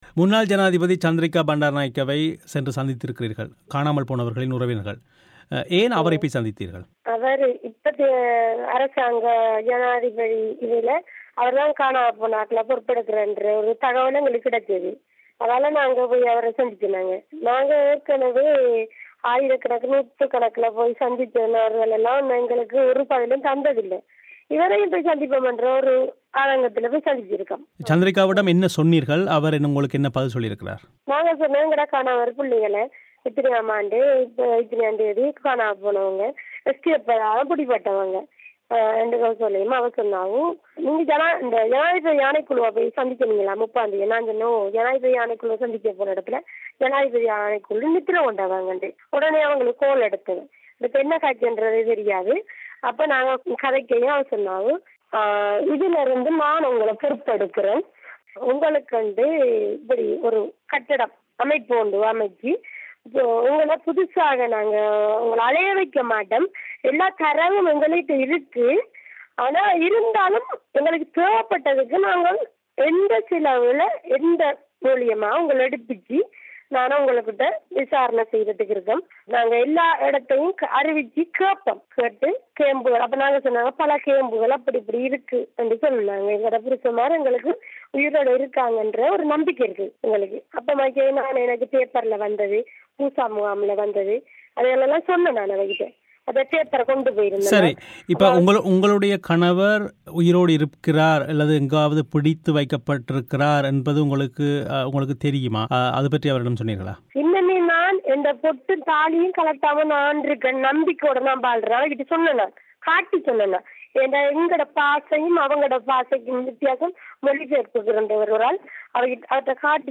செவ்வியை